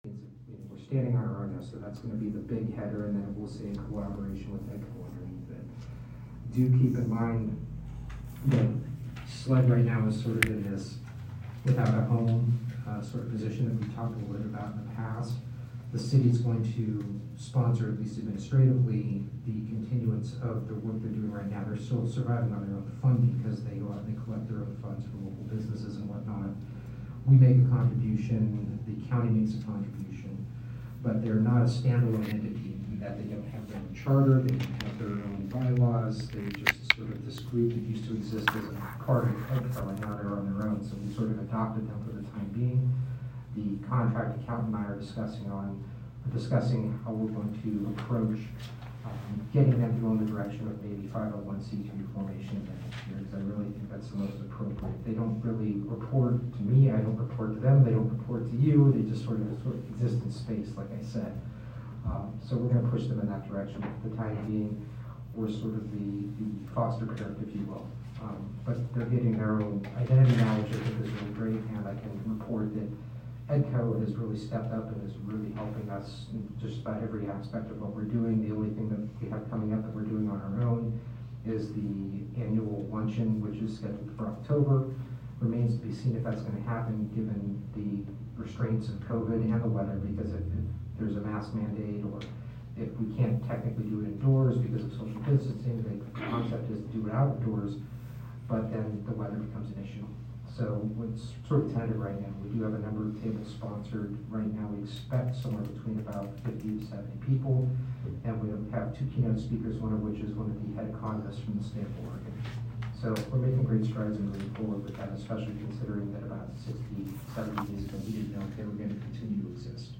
Regular City Council Meeting | City of La Pine Oregon
regular_city_council_08.25.21.mp3